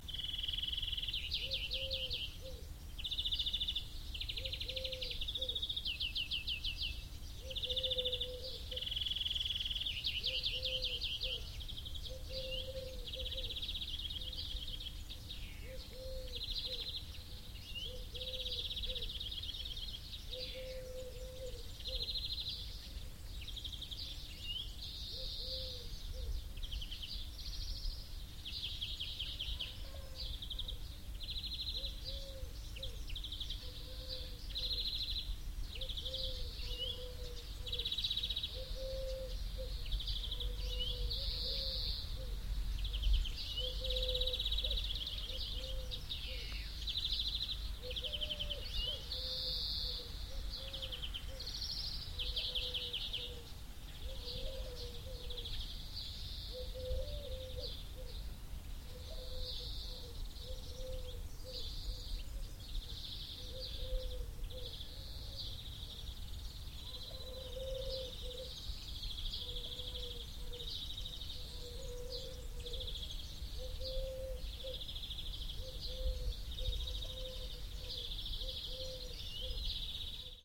Здесь вы найдете подборку умиротворяющих звуков: от шелеста листвы до мерного гудения кофейни.
Вечерний покой в деревне (звук из зоны комфорта)